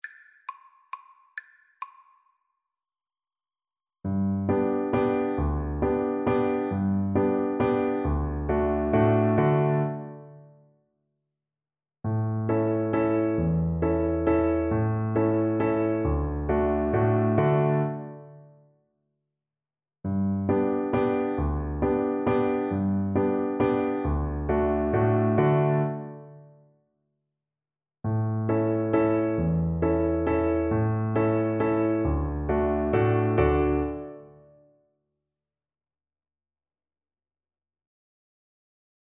One in a bar .=c.45
3/4 (View more 3/4 Music)